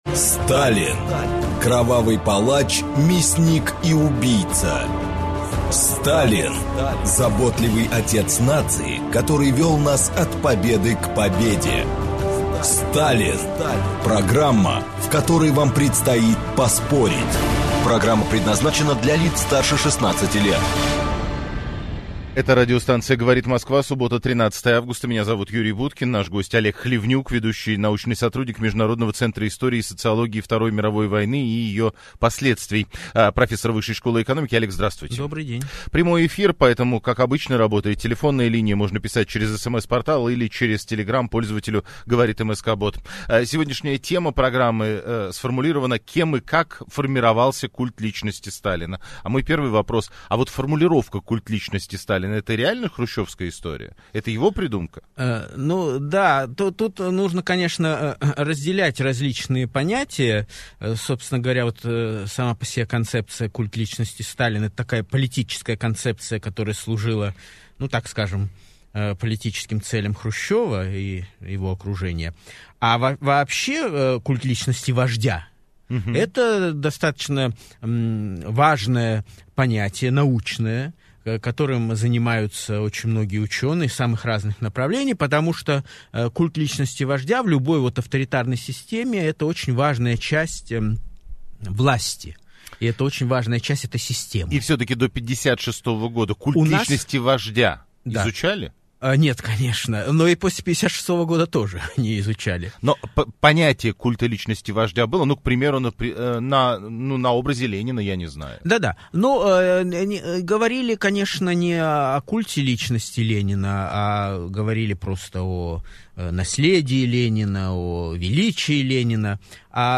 Аудиокнига Культ Сталина | Библиотека аудиокниг